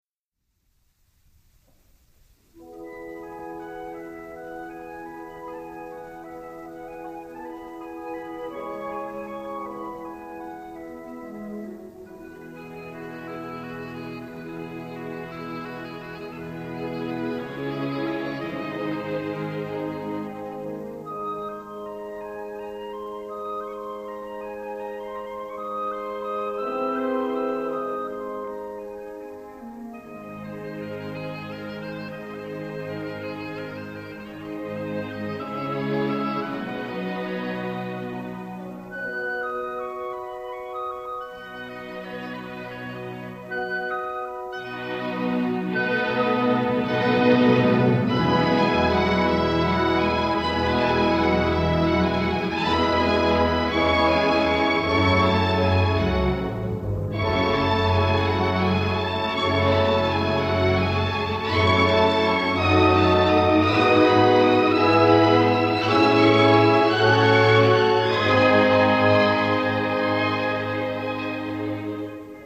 クラシック音楽の曲名